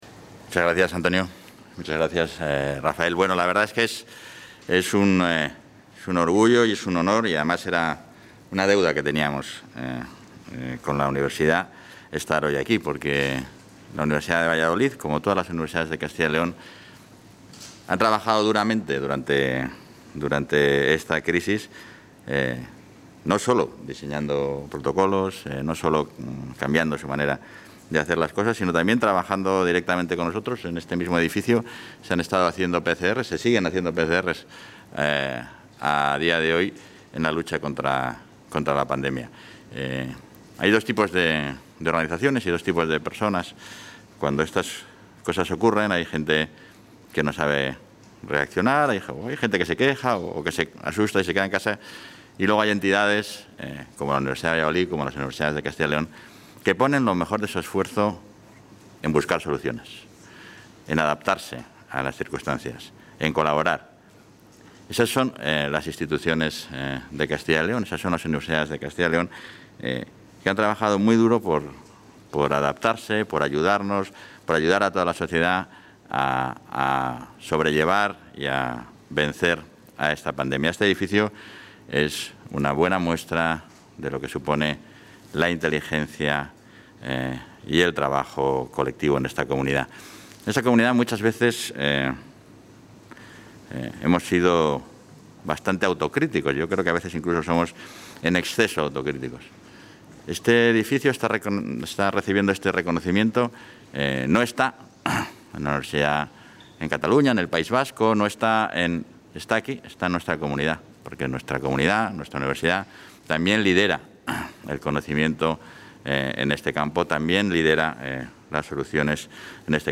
Intervención del vicepresidente.